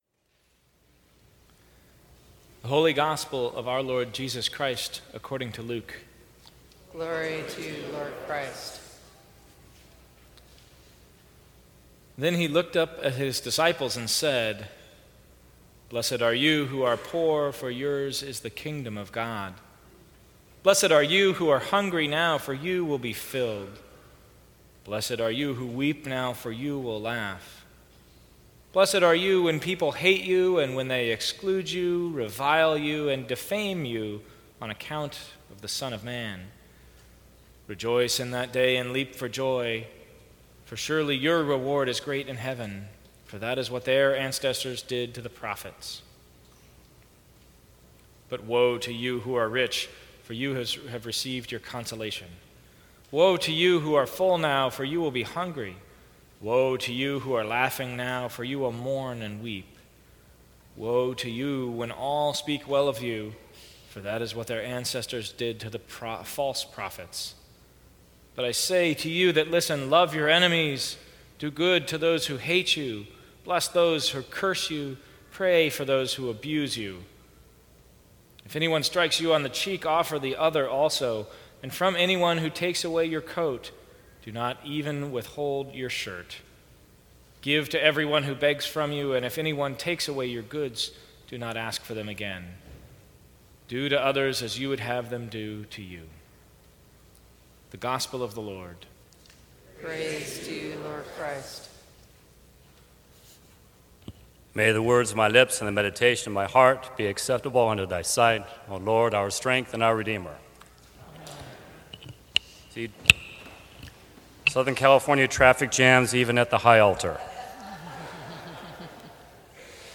Sermons from St. Cross Episcopal Church